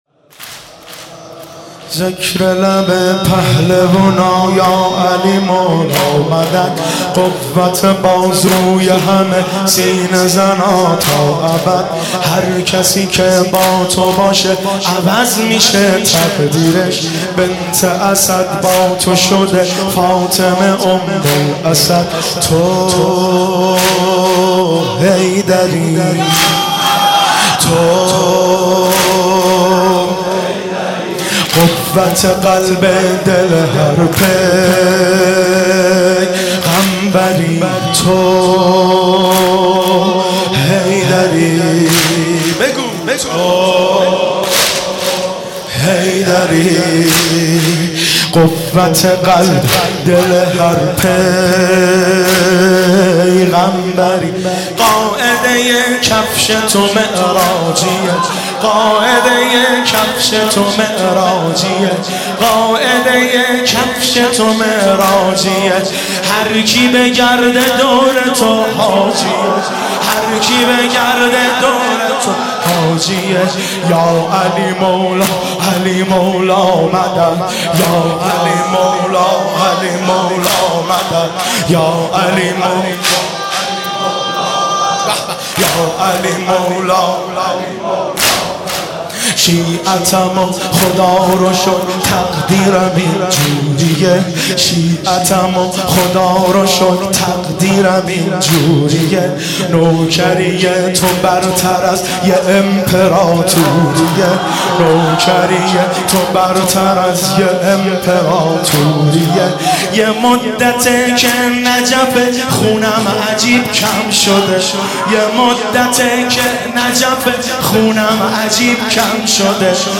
شب سوم رمضان 95، حاح محمدرضا طاهری